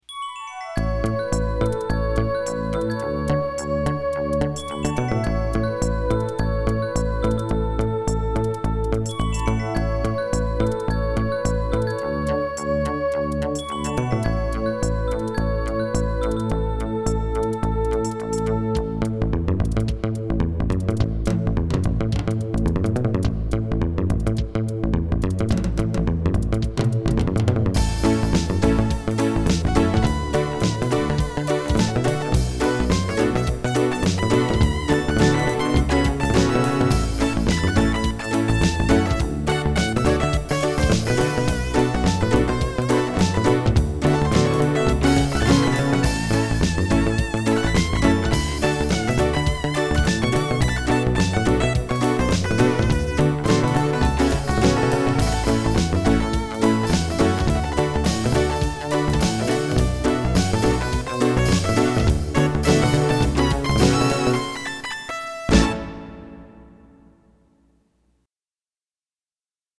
MT-32 MIDI conversion
As recorded from the original Roland MT-32 score